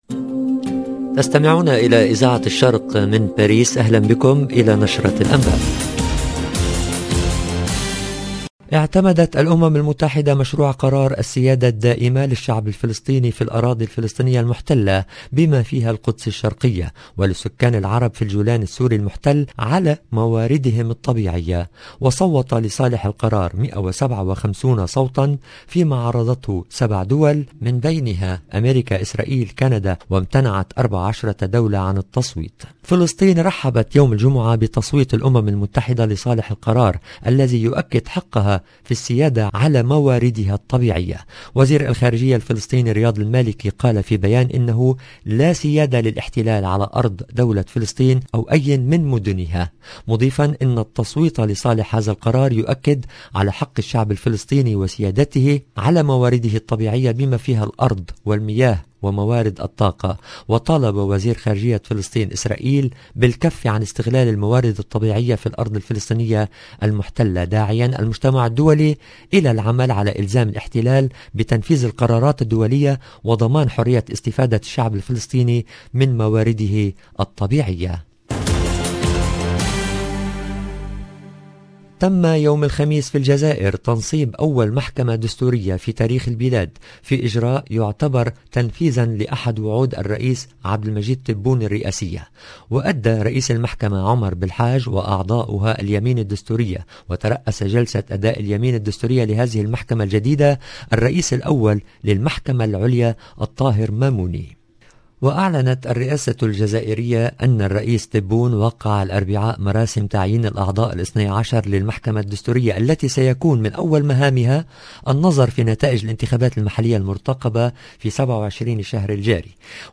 LE JOURNAL DU SOIR EN LANGUE ARABE DU 19/11/21